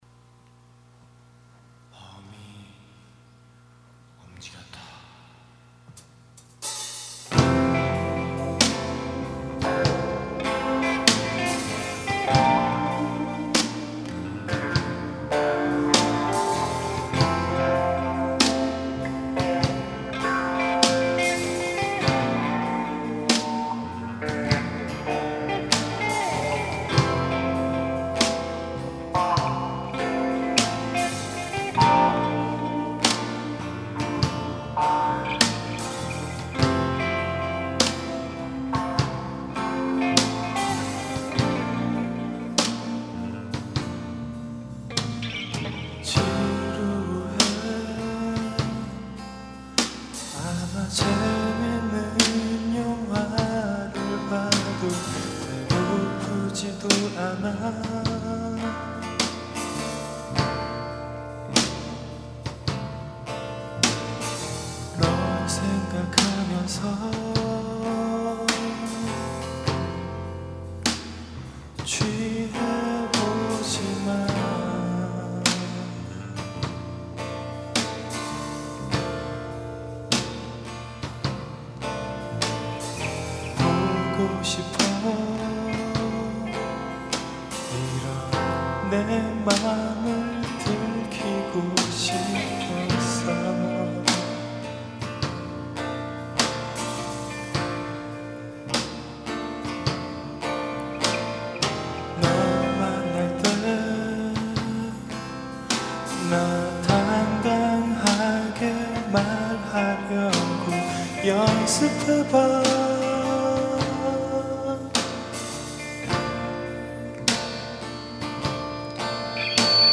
2005년 신입생 환영공연
홍익대학교 신축강당
어쿠스틱기타
일렉트릭기타
드럼